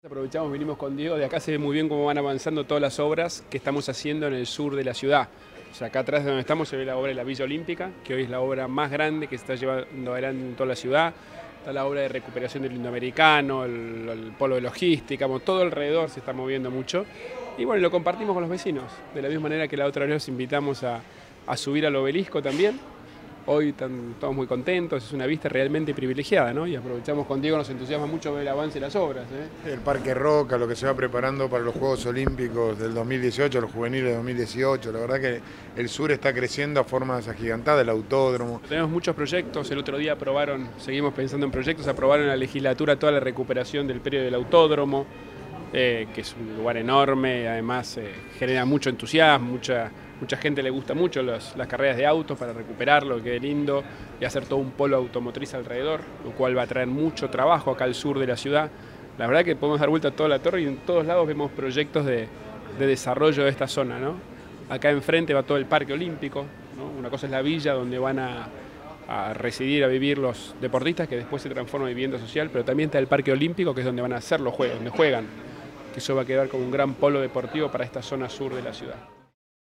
El jefe de Gobierno de la Ciudad de Buenos Aires, Horacio Rodríguez Larreta, subió a la Torre de la Ciudad junto a un grupo de 200 vecinos que quedaron seleccionados a través de un concurso de Participación Ciudadana, y destacó que la iniciativa permitió además “ver cómo están avanzando las obras” de la zona sur.